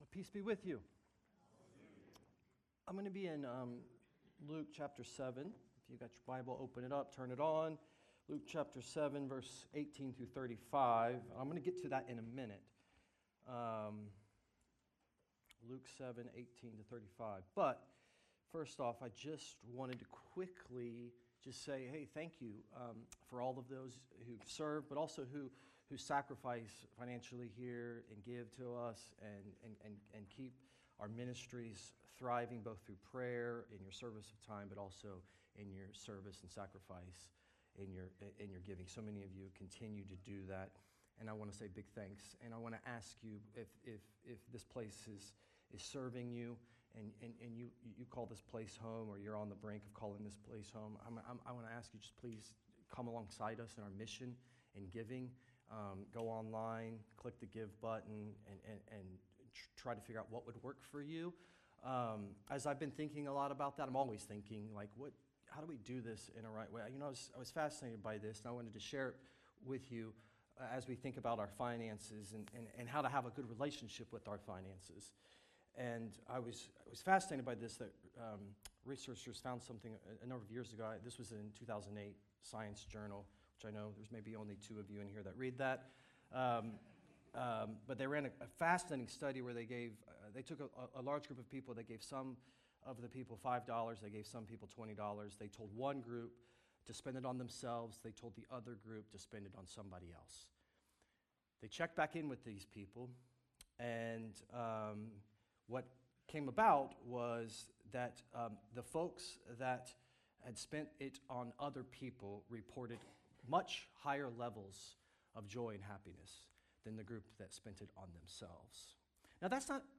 Tune in for sermons from The Oaks Community Church in Middletown, Ohio.
Sermons